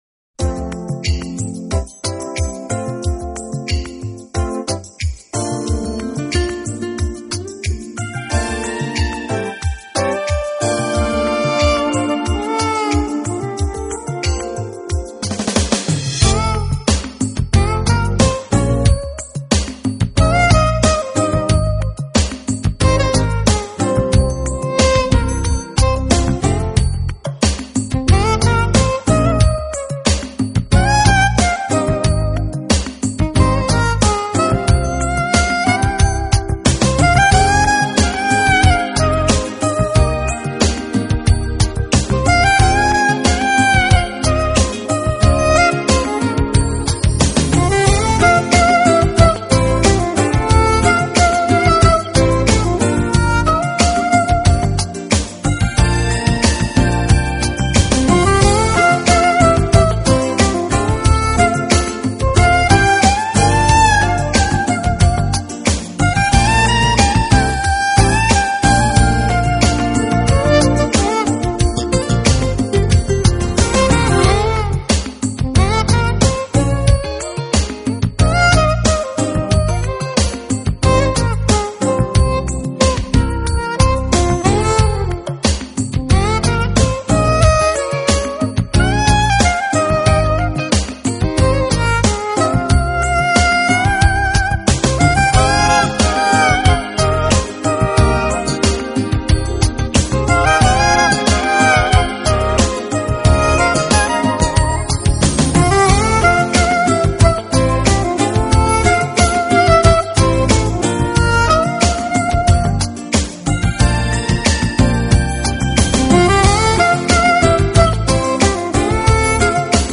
【爵士萨克斯】
音乐类型：Jazz